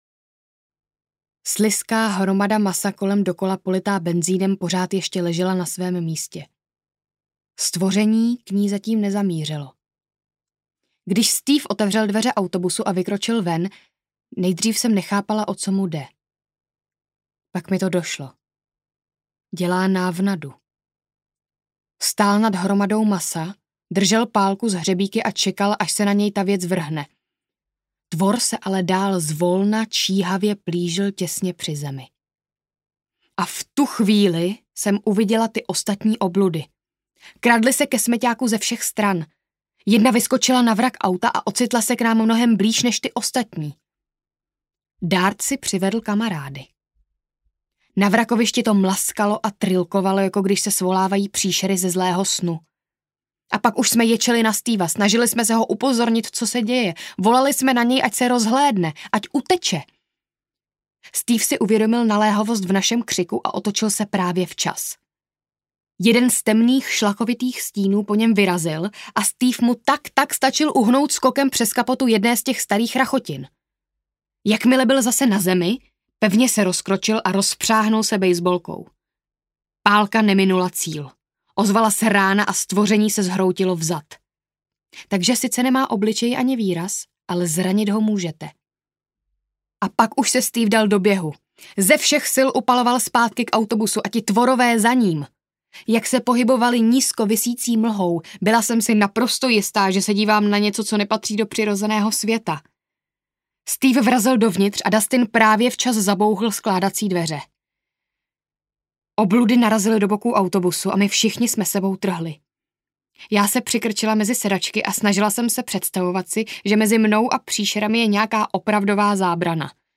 Stranger Things: Šílená Max audiokniha
Ukázka z knihy